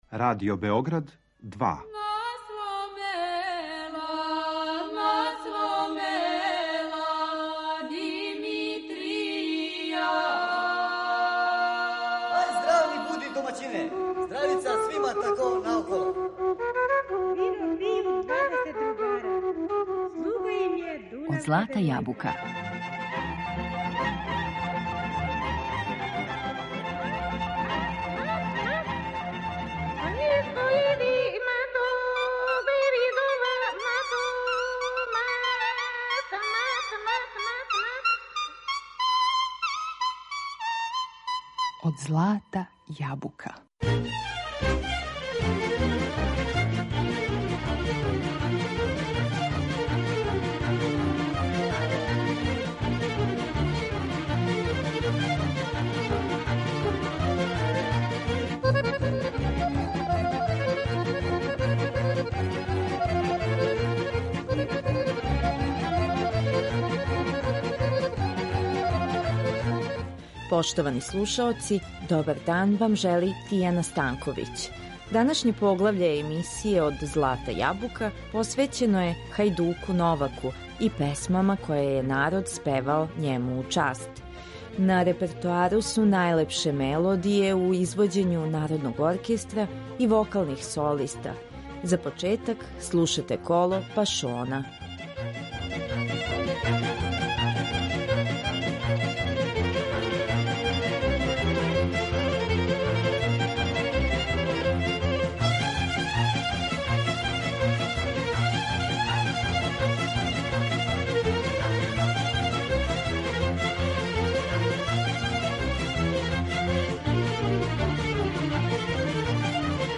Народ их је у песми помешао, стопивши их у једну личност. У данашњем издању емисије Од злата јабука , а на темељу истраживања наших признатих стручњака, покушаћемо да одгонетнемо ову нејасноћу, уз звуке Народног оркестра и наше поштоване вокалне солисте.